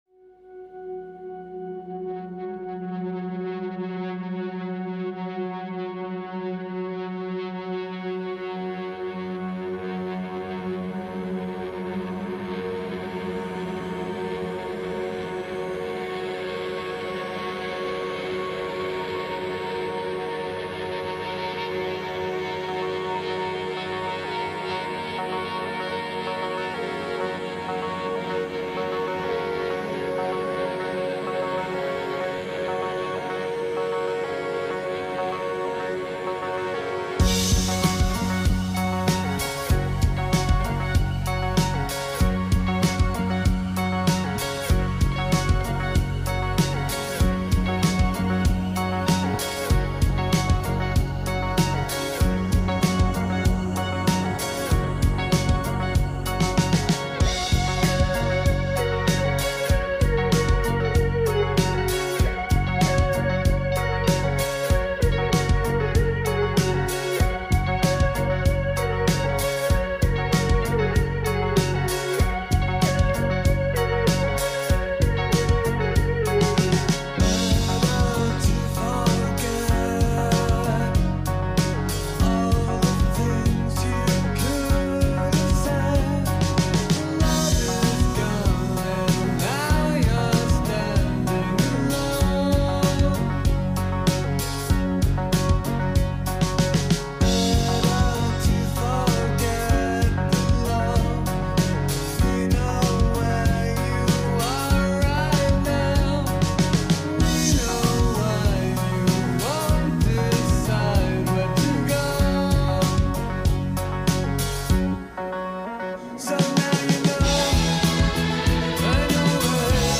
Indie with a Shoegaze ethic.